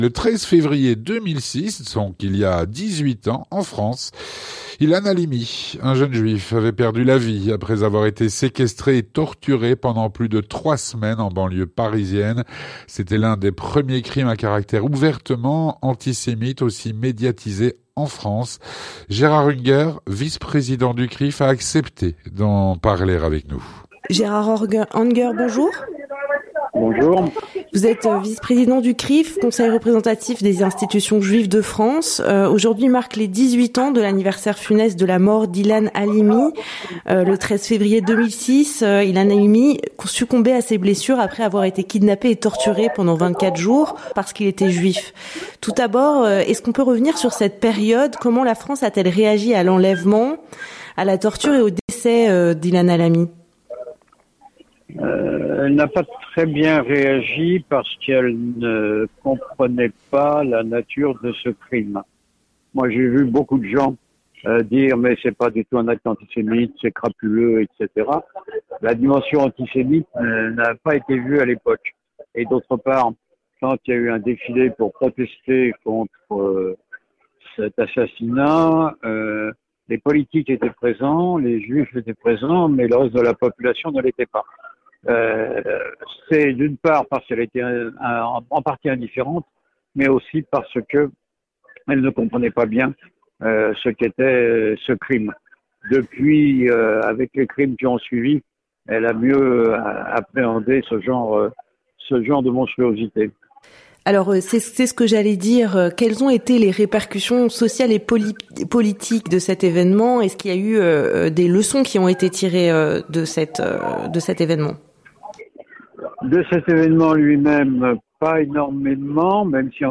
L'entretien du 18H